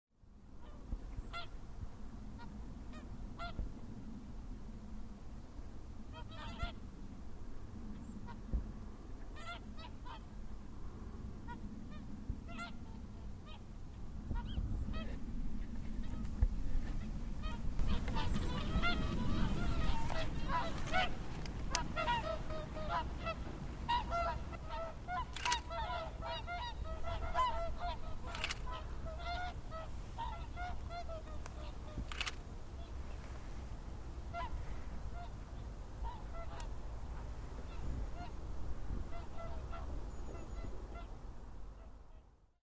A herd of about thirty Whooper swans recorded on the Suir flood plain near Tybroughney Castle.